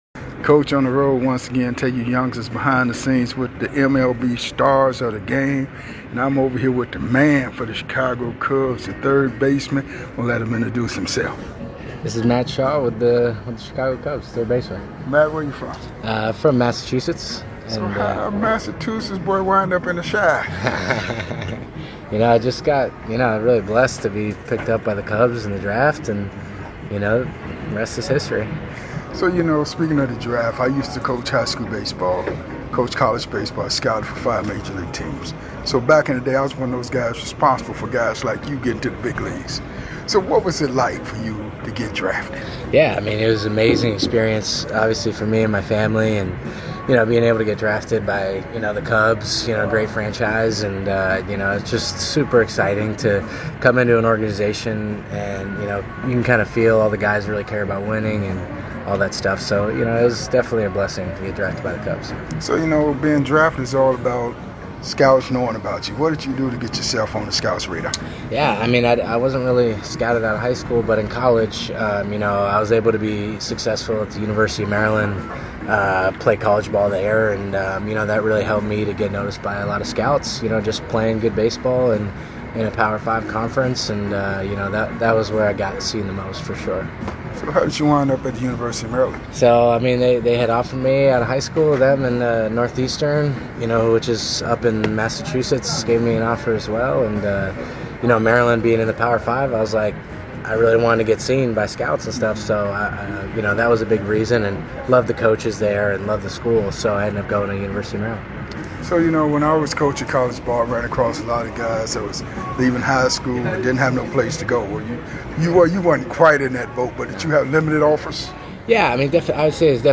⚾ MLB Classic Interviews